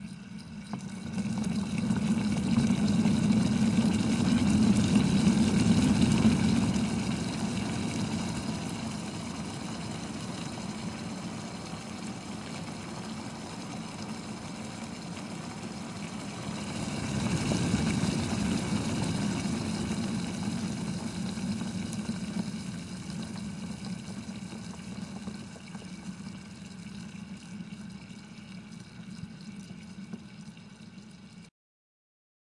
Kettle start to end
描述：boiling water in metal kettle
标签： OWI kettle boiling water
声道立体声